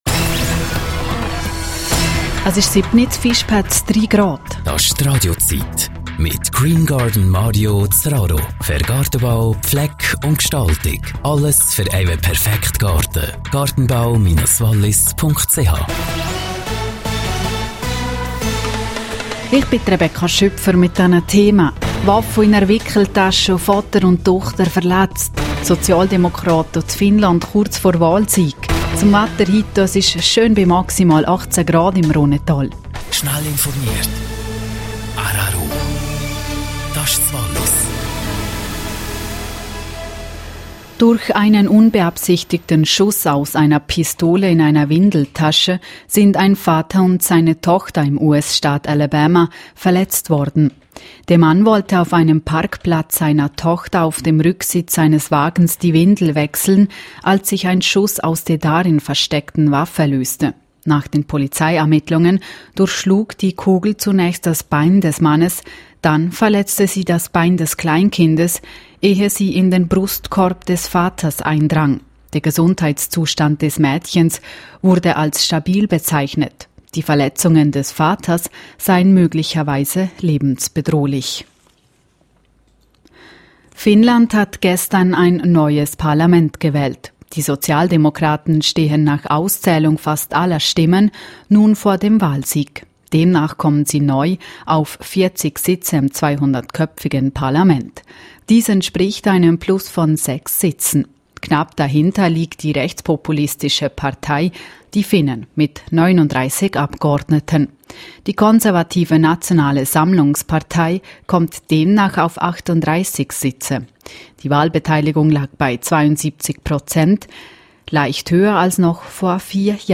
Der rro-Montagsberliner für MitMenschen Oberwallis. Das Überraschungstelefon.